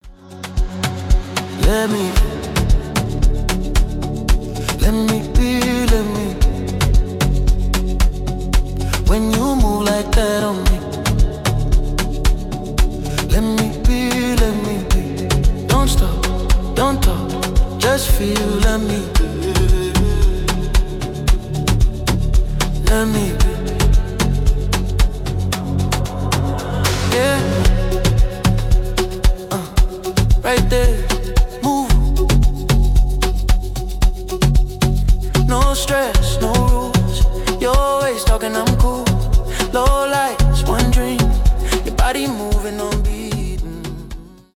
Категория: Танцевальные рингтоныЗарубежные рингтоны